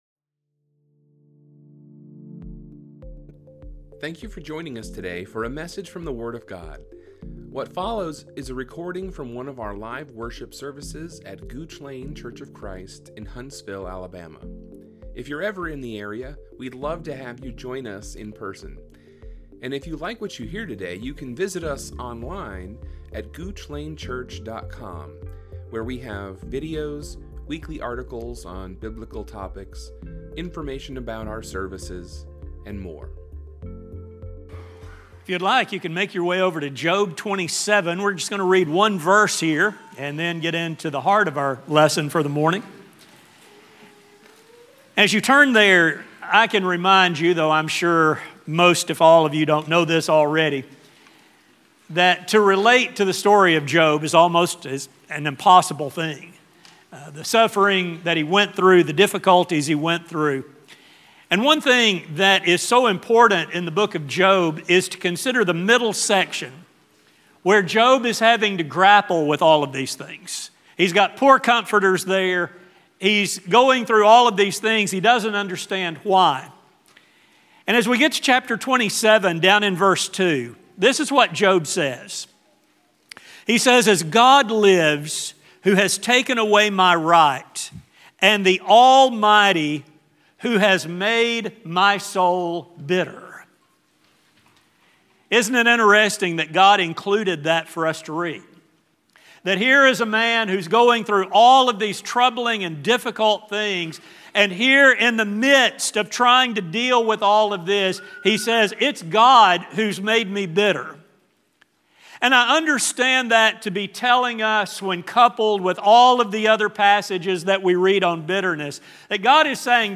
This sermon will explore what Scripture teaches about the problem of bitterness—what causes it and how citizens of the kingdom are called to put it away.